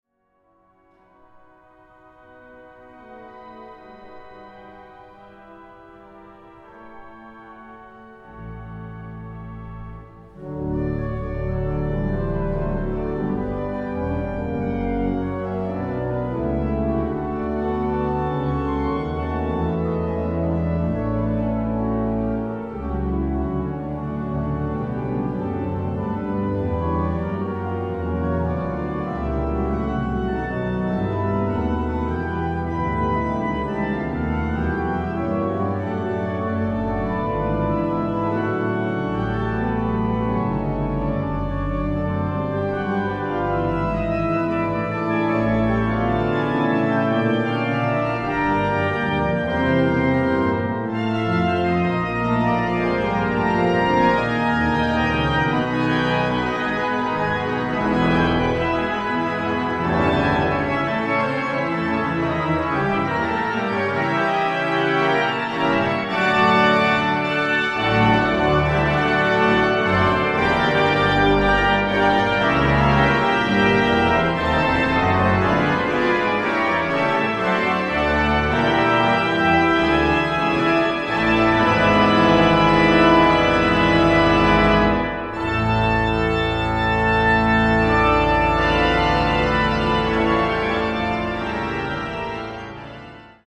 Konzert CD
WALCKER-Orgel von 1928.
op. 31, 1. Satz Allegro